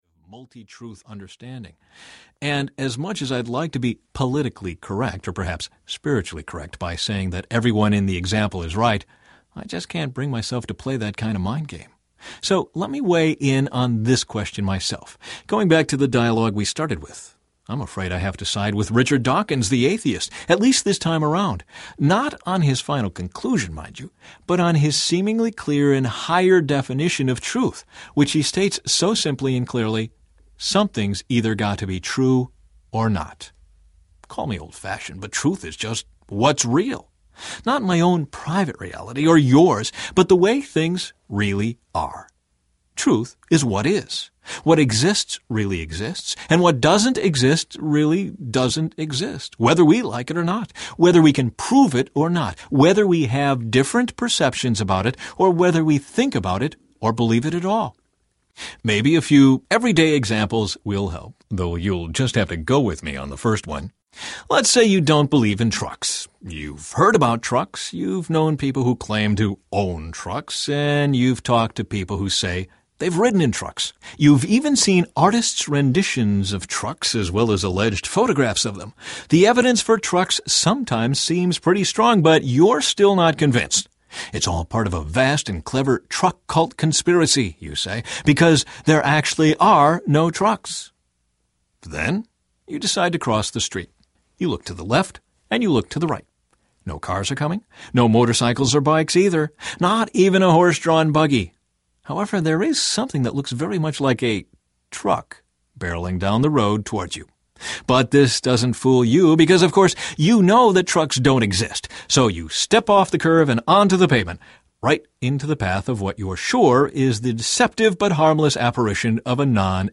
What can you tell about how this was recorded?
6.4 Hrs. – Unabridged